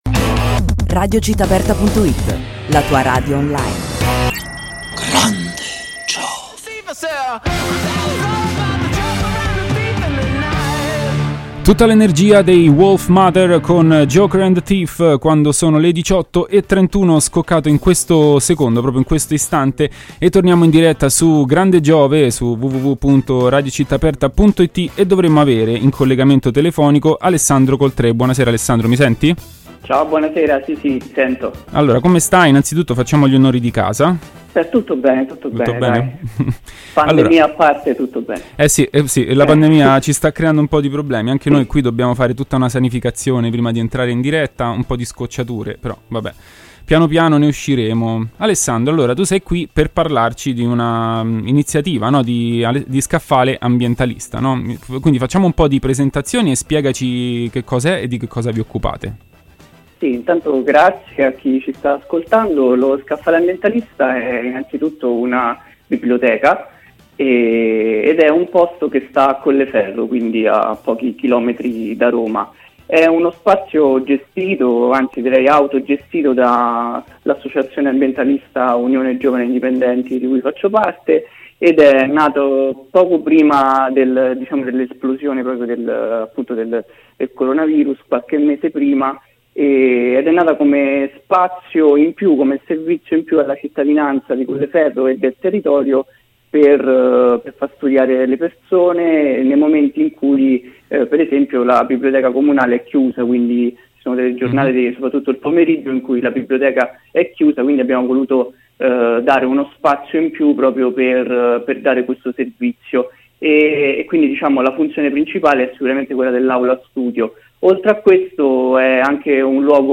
Radio Città Aperta
intervista-scaffale-ambientalista.mp3